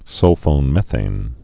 (sŭlfōn-mĕthān, -fŏn-)